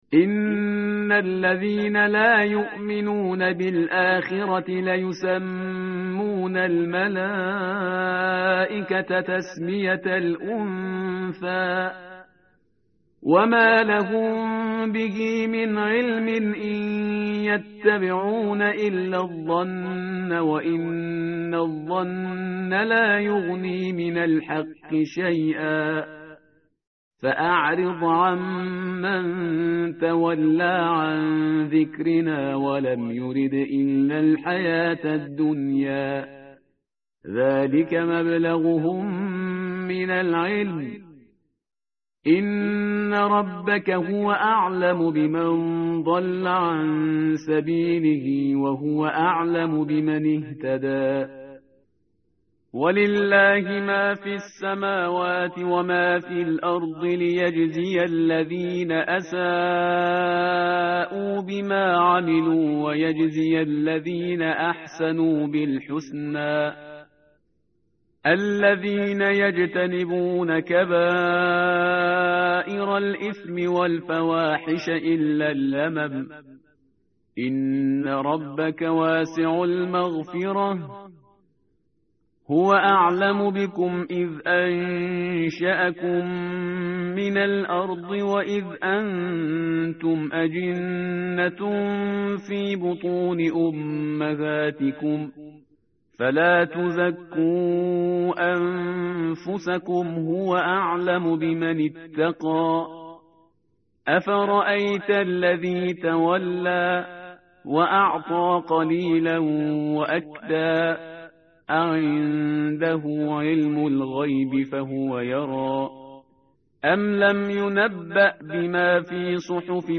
tartil_parhizgar_page_527.mp3